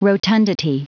Prononciation du mot rotundity en anglais (fichier audio)
Prononciation du mot : rotundity